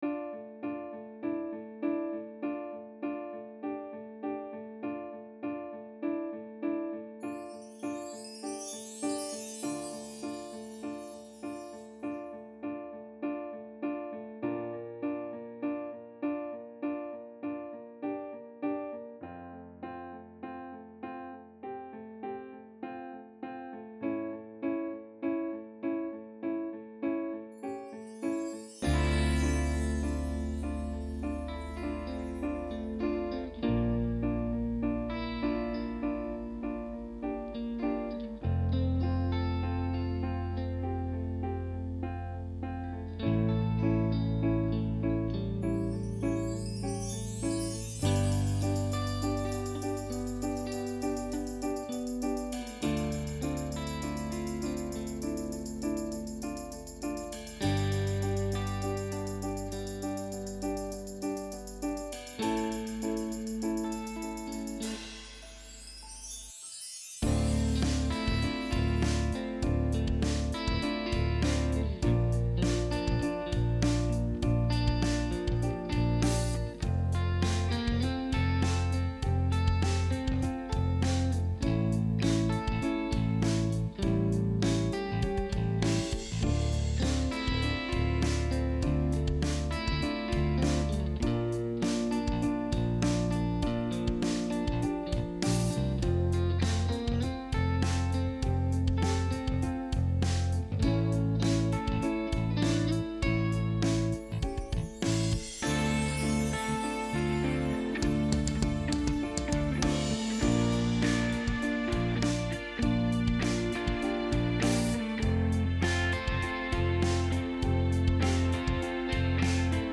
Авторская песня.
А для выступления сделали минус. Из живого здесь - электрогитара в один трек, пока не дублированная в стерео.